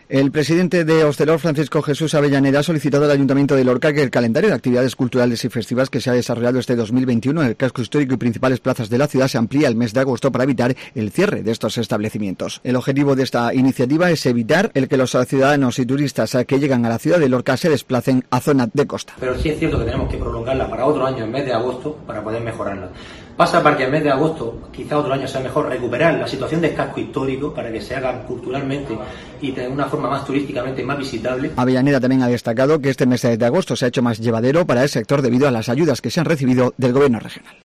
INFORMATIVO MEDIODÍA LORCA MIÉRCOLES